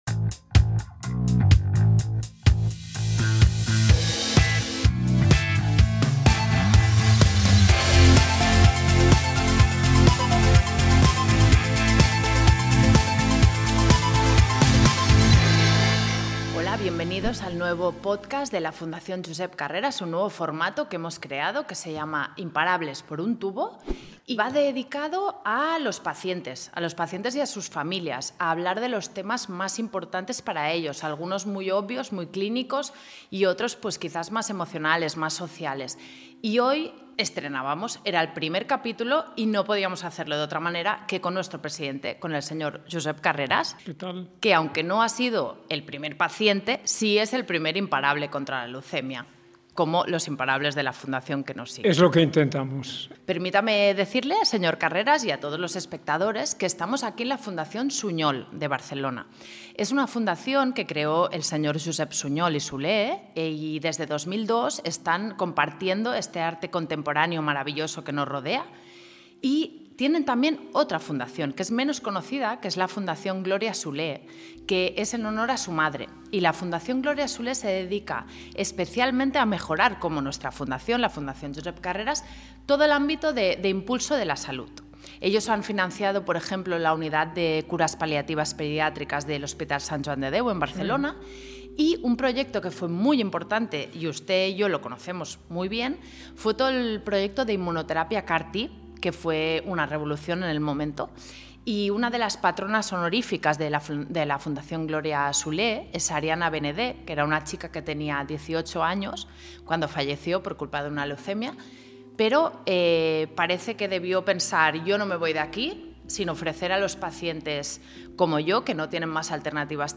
Este capítulo se ha realizado en las instalaciones de la Fundación Josep Suñol de Barcelona.